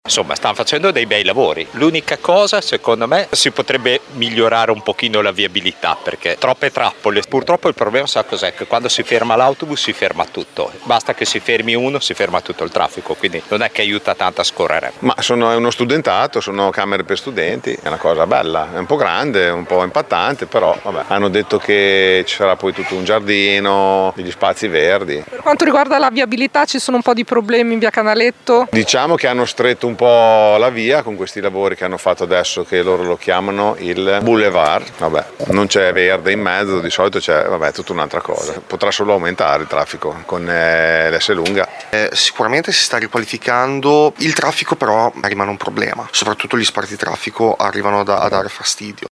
Ma sentiamo alcune opinioni sugli interventi in atto nel quartiere:
VOX-CANALETTO.mp3